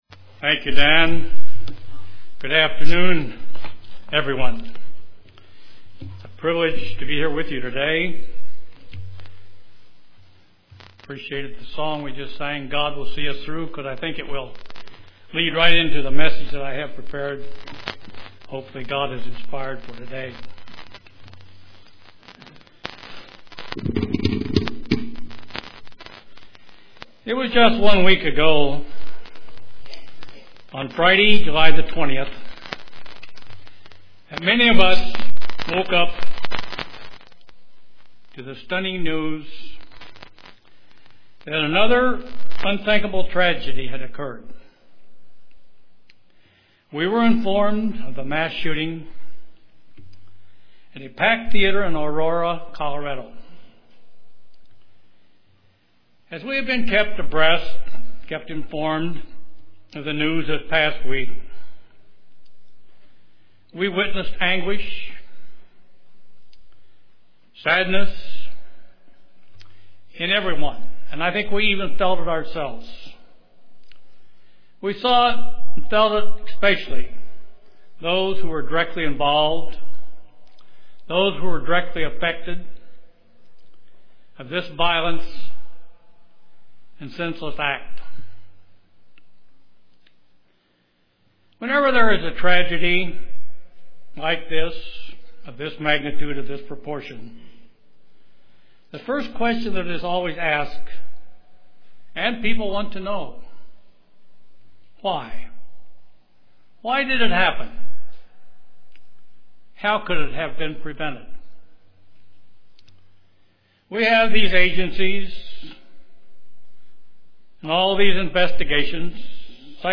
Sermons
Given in Kansas City, KS